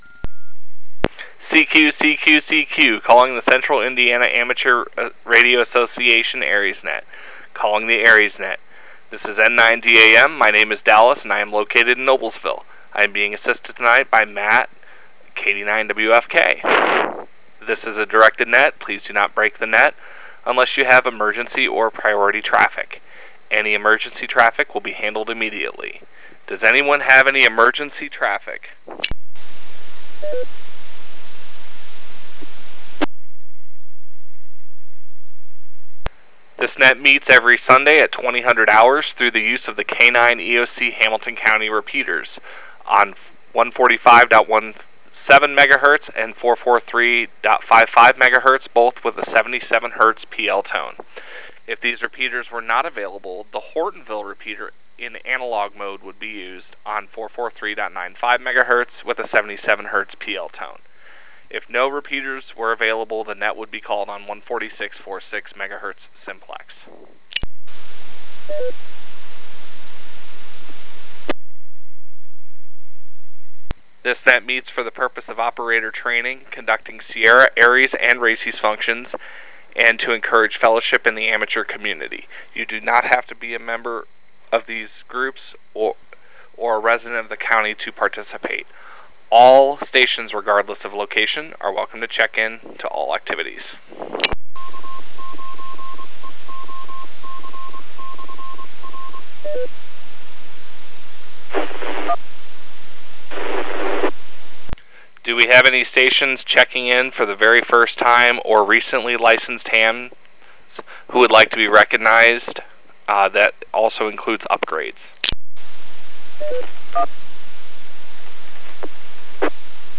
Media from the Thursday Night Ciara Education Net.